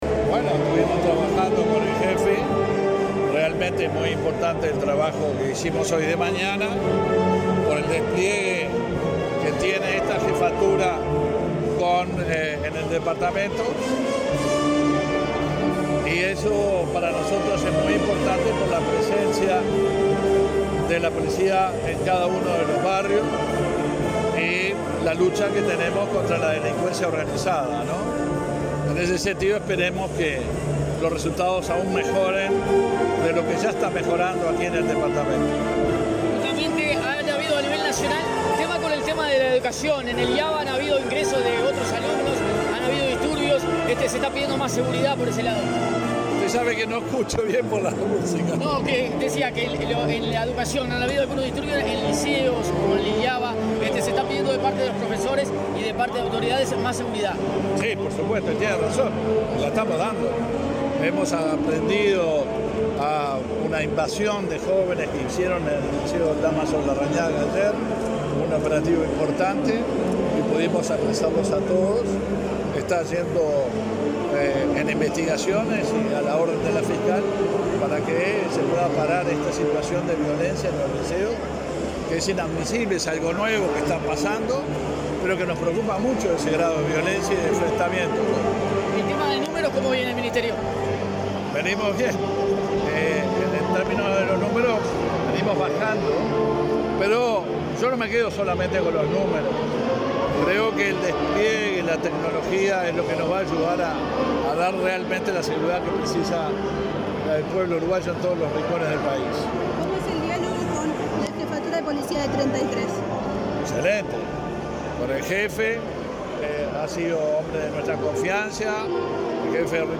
Declaraciones del ministro del Interior, Luis Alberto Heber
Luego dialogó con la prensa.